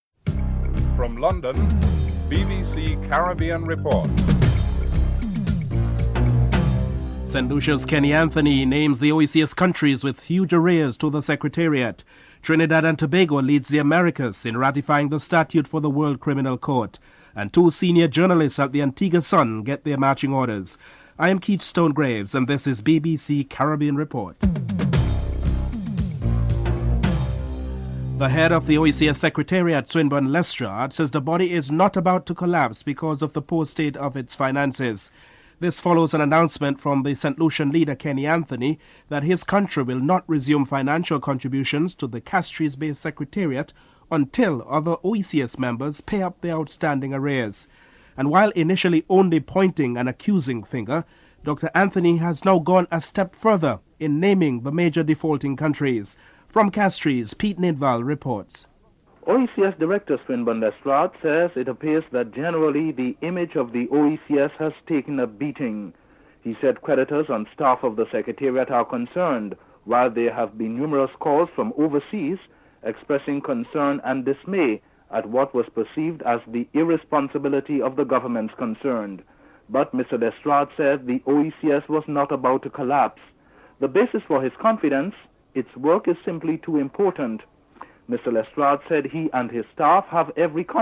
Brian Lara speaks on the victory and work of the cricket team. Australia captain Steve Waugh comments on his team losing the match (12: 08 - 15: 26)